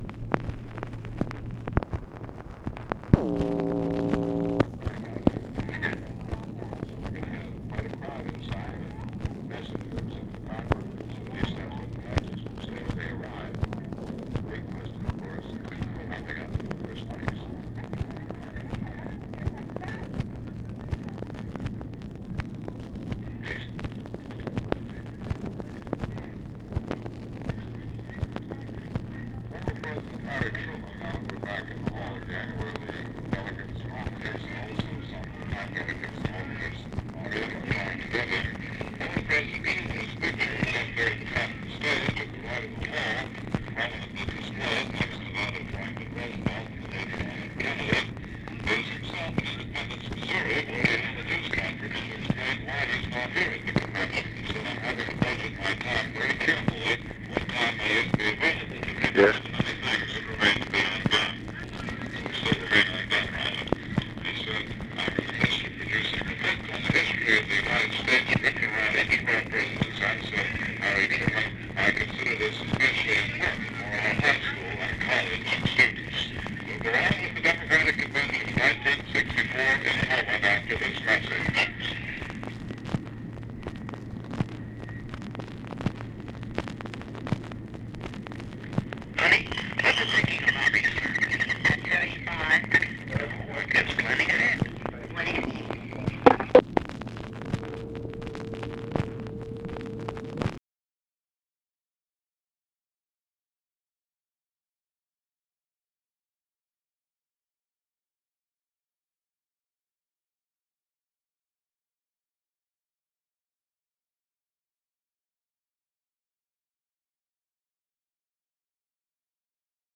ONLY SPOKEN WORD IS "YES"
OFFICE NOISE, August 25, 1964
Secret White House Tapes | Lyndon B. Johnson Presidency